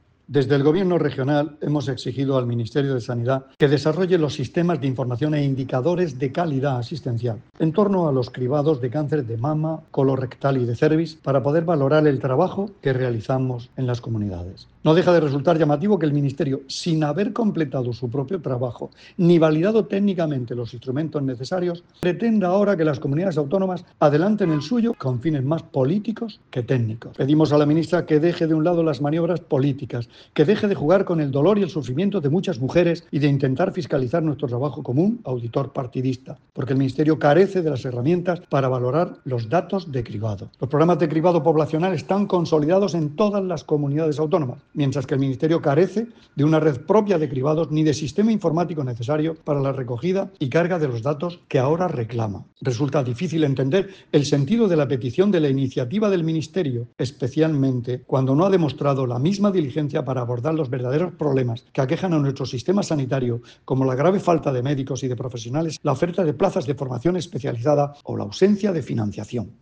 Declaraciones del consejero de Salud, Juan José Pedreño, sobre la petición del Ministerio de datos de cribado de cáncer.